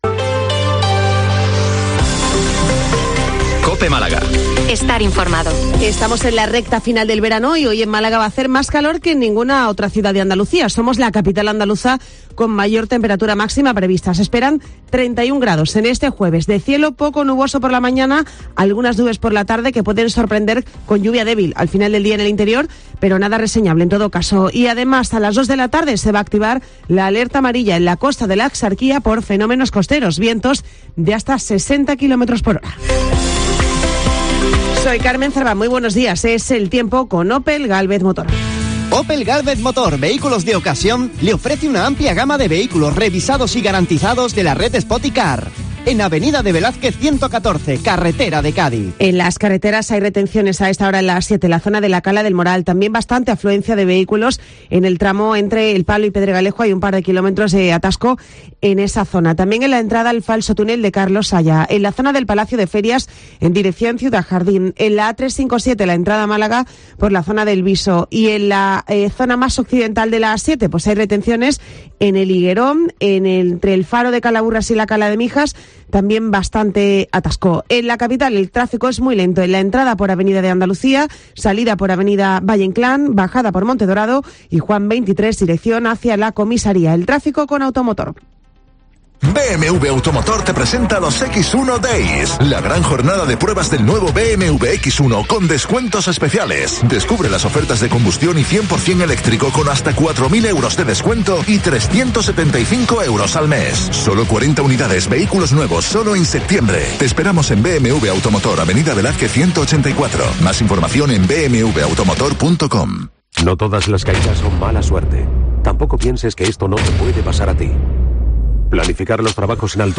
Informativo 08:24 Málaga - 0824